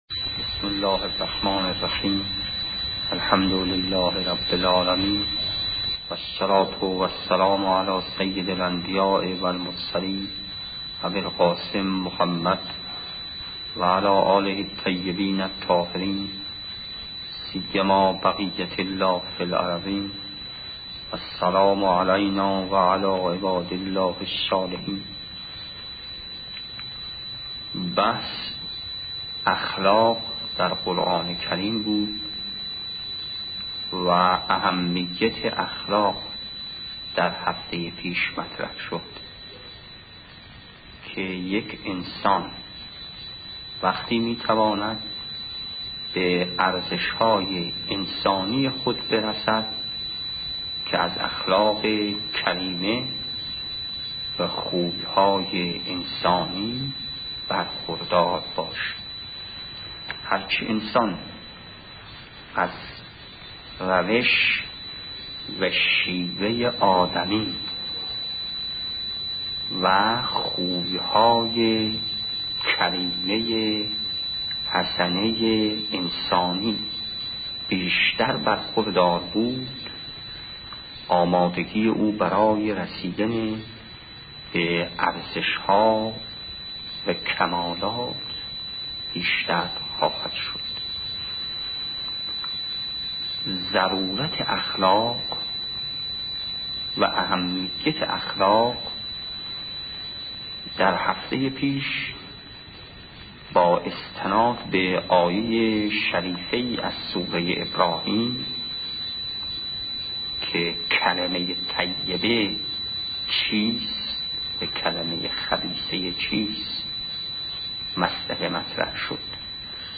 آيت الله امامي کاشاني - اخلاق در قرآن کریم | مرجع دانلود دروس صوتی حوزه علمیه دفتر تبلیغات اسلامی قم- بیان